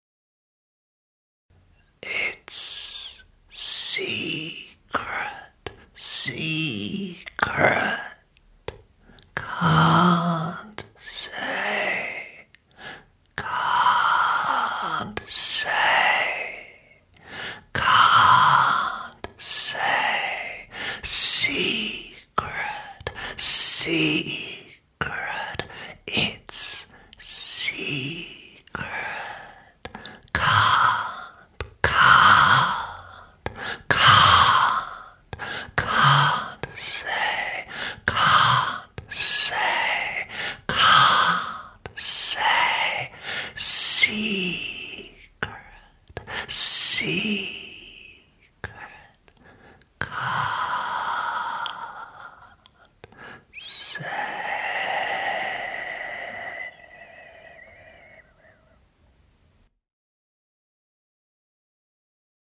A variety of polypoetry launched during the 2011 Melbbourne Overload Poetry Festival featuring :